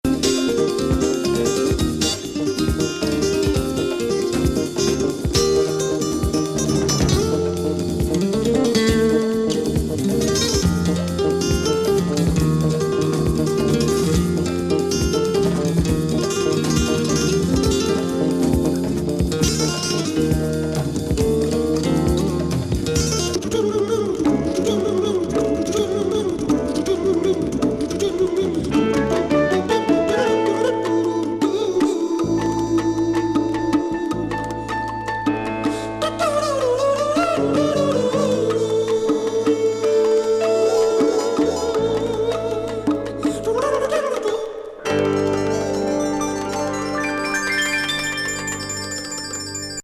プログレッシブ・フュージョン
シンセ・サンバ
・コーラスの天界サウンド「CANCION PARA CELEBRAR EL